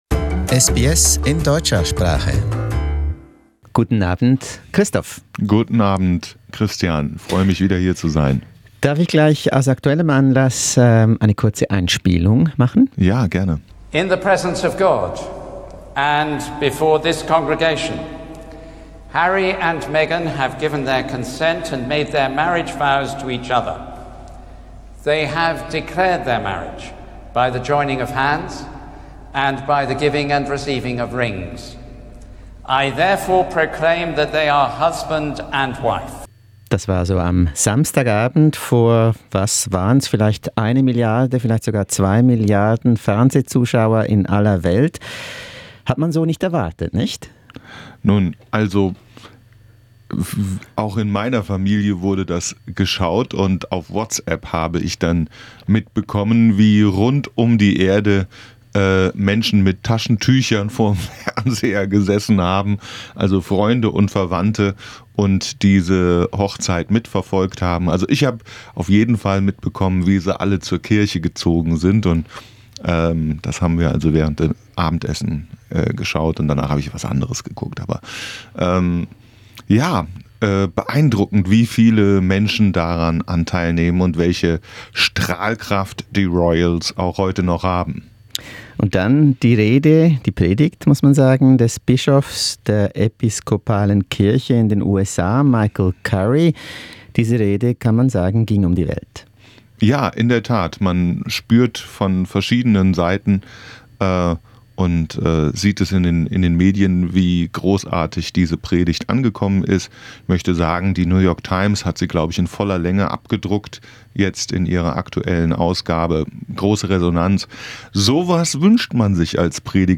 Studiointerview